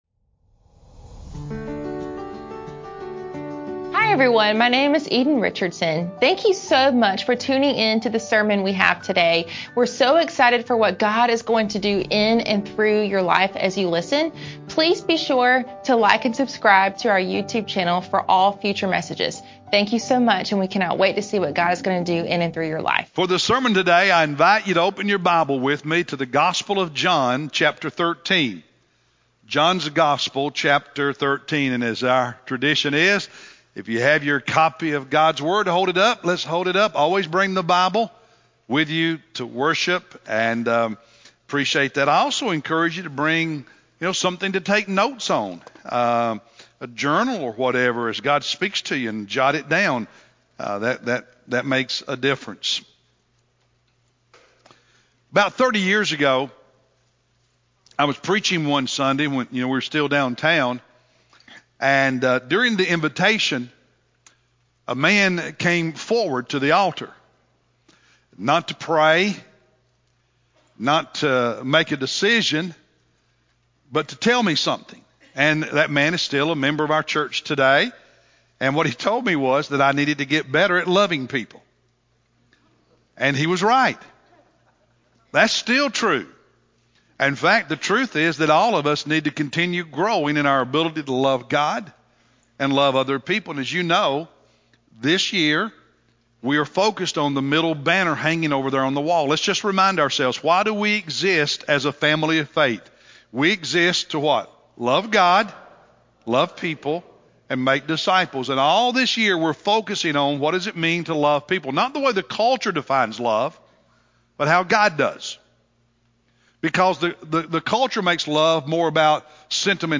April-12-Sermon-CD.mp3